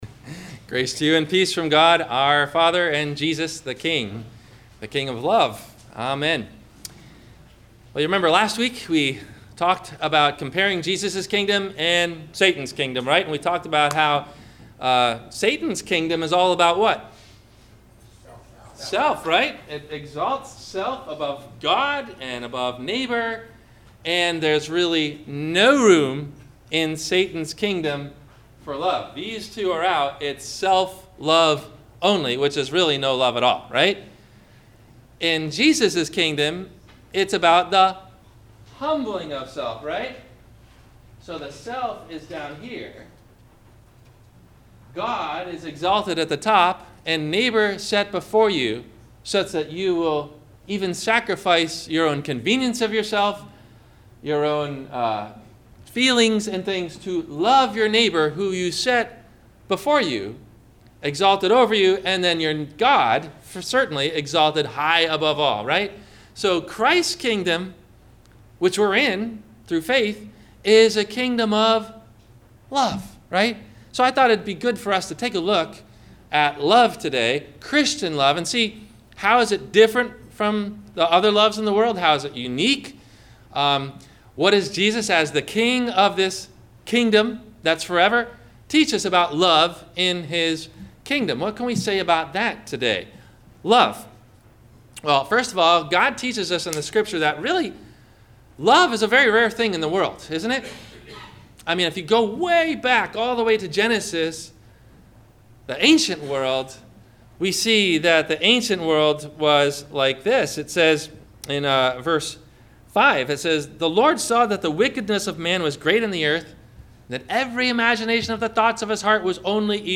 What Does It Mean To Love? - Sermon - June 12 2016 - Christ Lutheran Cape Canaveral